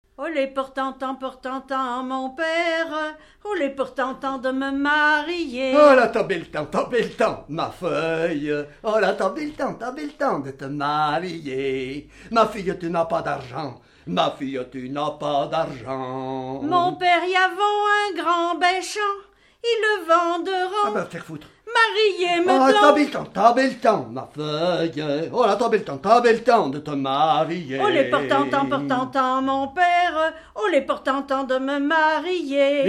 en duo
Dialogue mère-fille
Pièce musicale inédite